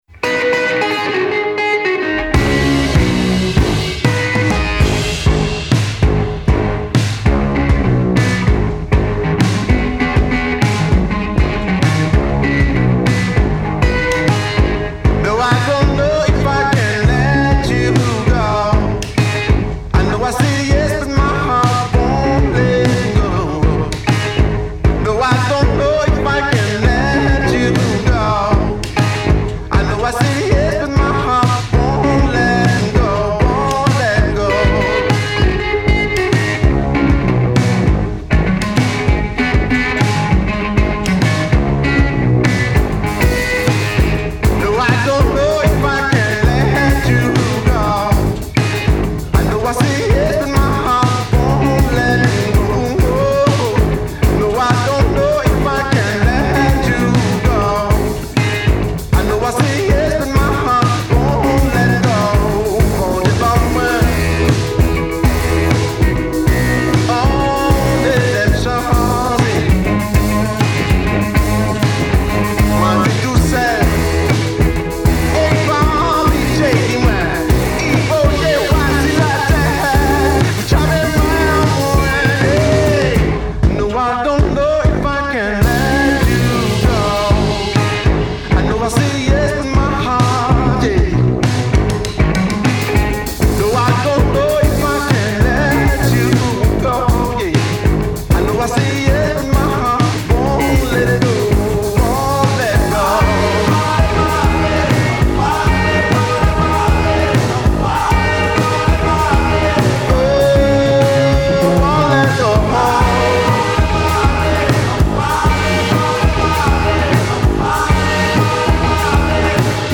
Жанр: Blues.